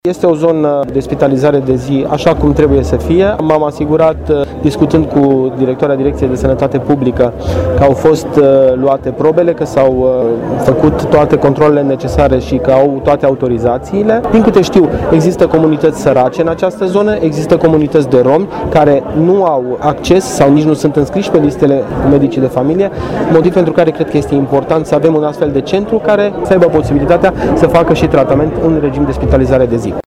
La eveniment au participat și doi miniștri, Marius Dunca, ministrul Tineretului și Sportului, care l-a adus la Săcele pe Florian Bodog, ministrul Sănătații. Acesta a apreciat redeschiderea unității medicale, care ar putea astfel degreva de spitalizări continue Spitalul Județean Brașov.